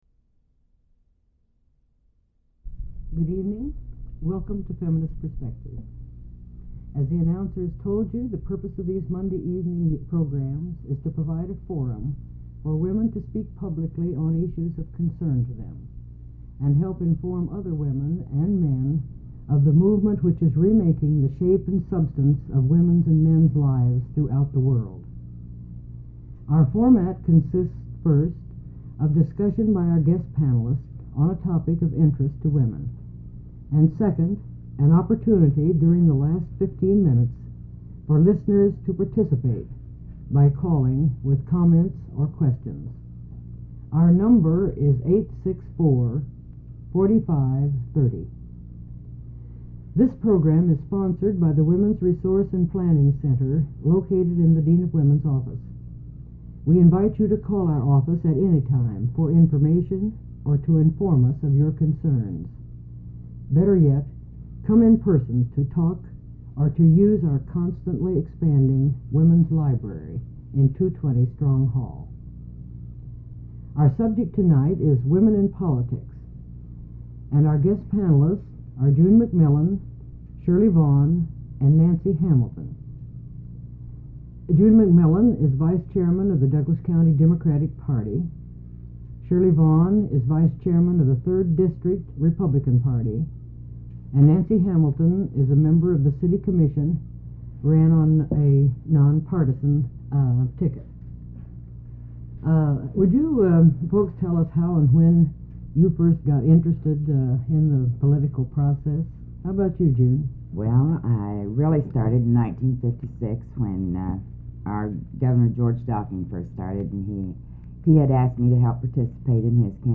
Feminism and higher education Women's rights Women college students Radio talk shows